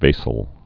(vāsəl, -zəl)